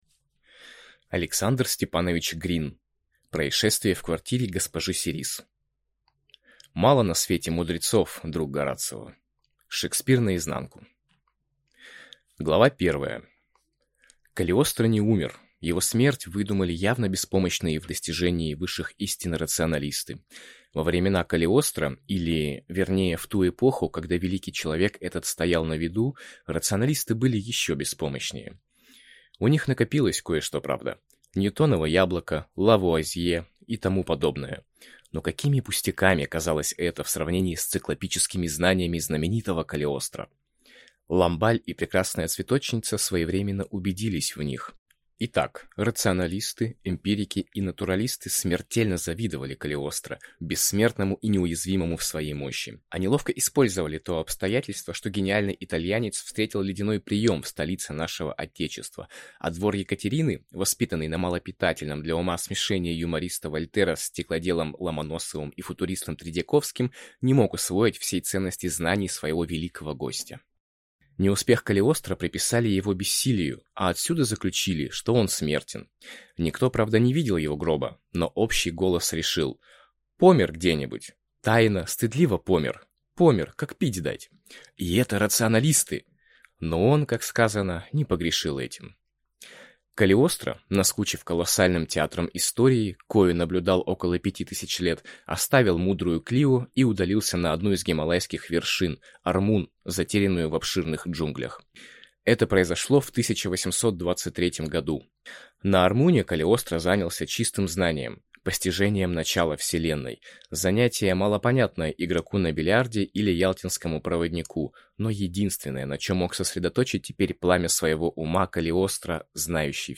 Аудиокнига Происшествие в квартире г-жи Сериз | Библиотека аудиокниг